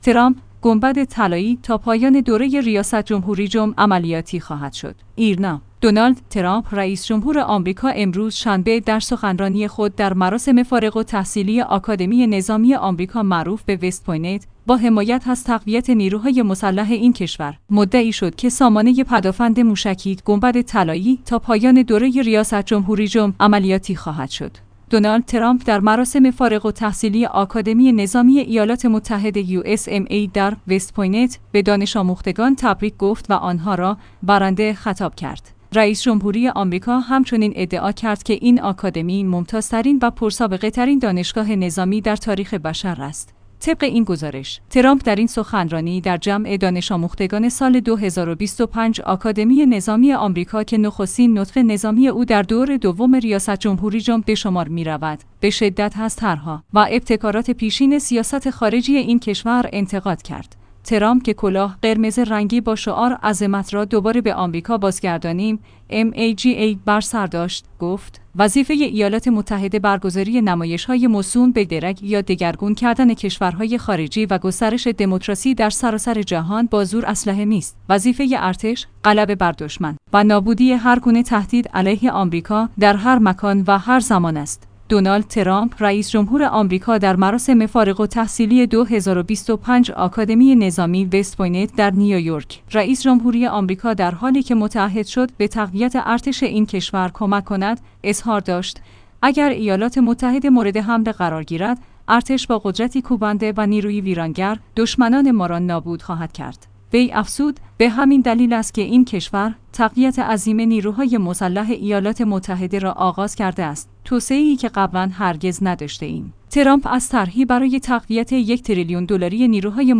ایرنا/ «دونالد ترامپ» رئیس‌جمهور آمریکا امروز (شنبه) در سخنرانی خود در مراسم فارغ التحصیلی آکادمی نظامی آمریکا معروف به «وست پوینت»، با حمایت از تقویت نیروهای مسلح این کشور، مدعی شد که سامانه پدافند موشکی «گنبد طلایی» تا پایان دوره ریاست‌جمهوری‌اش عملیاتی خواهد شد.